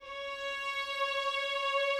strings_061.wav